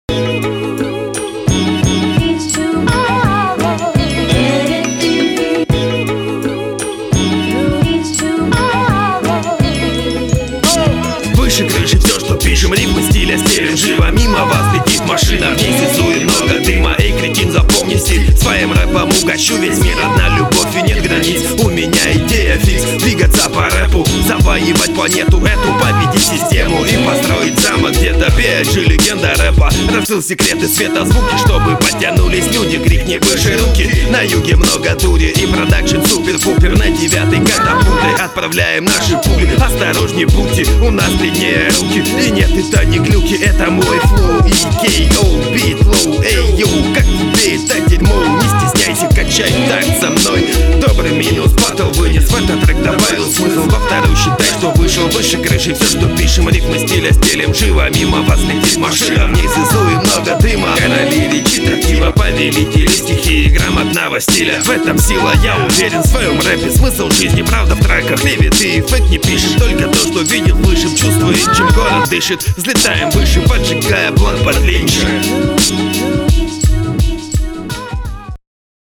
какой-то хриплокартаводефектный реп